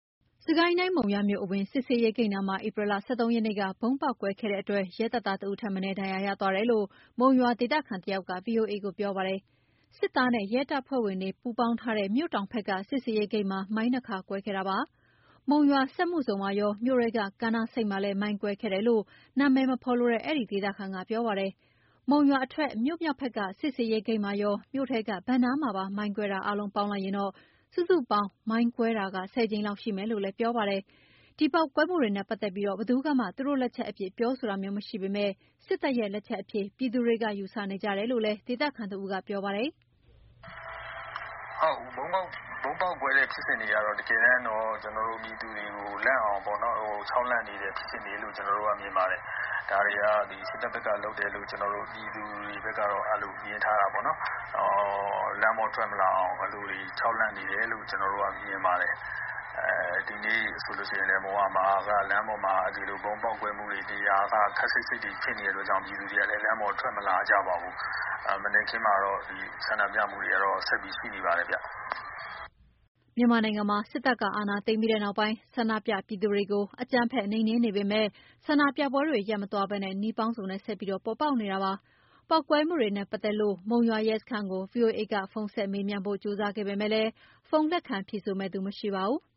စစ်ကိုင်းတိုင်း၊ မုံရွာမြို့အဝင်စစ်ဆေးရေးဂိတ်နားမှာ ဧပြီ ၁၃ ရက်နေ့က ဗုံးပေါက်ကွဲခဲ့တဲ့အတွက် ရဲတပ်သား တဦးထက်မနည်း ဒဏ်ရာရသွားတယ်လို့ မုံရွာဒေသခံတယောက်က ဗွီအိုအေကို ပြောပါတယ်။